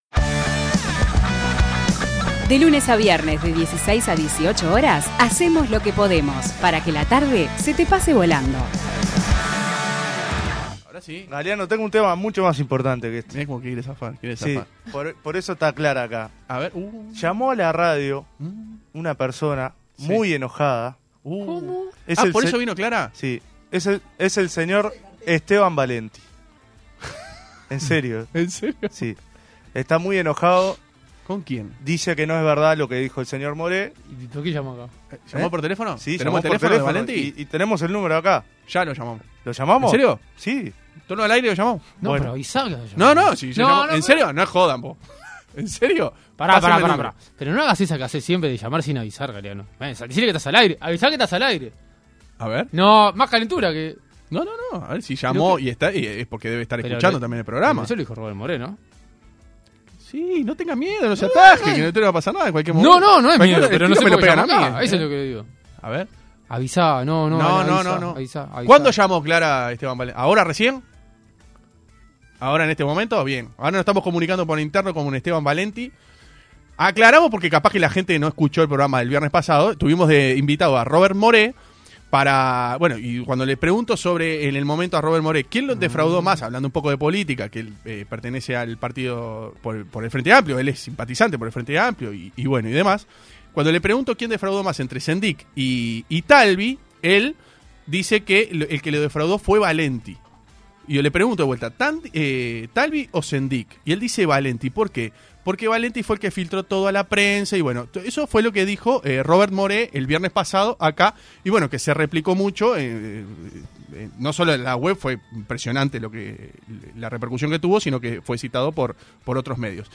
A raíz de estas declaraciones, el periodista se comunicó con 970 Universal y salió al aire en el ciclo vespertino para dar su versión de los hechos.